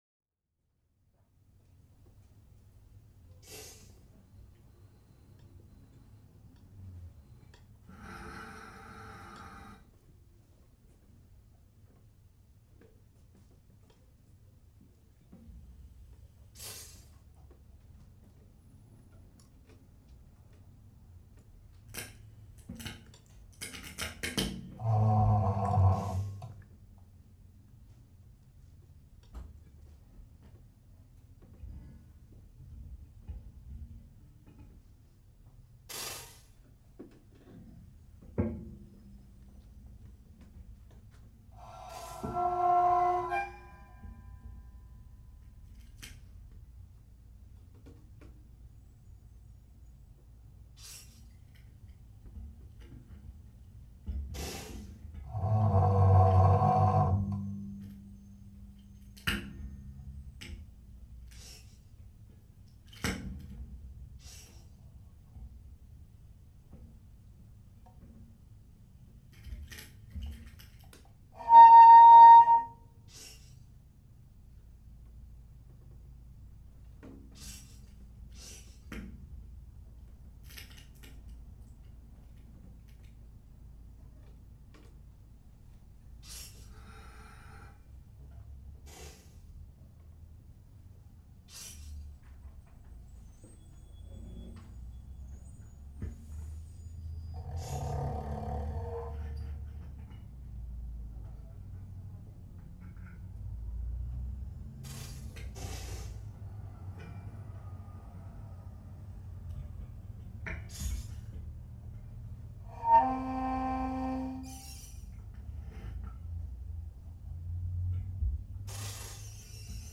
wiimote/Csound
percussion, electronics
cello, circuits. Played at The Stone, NYC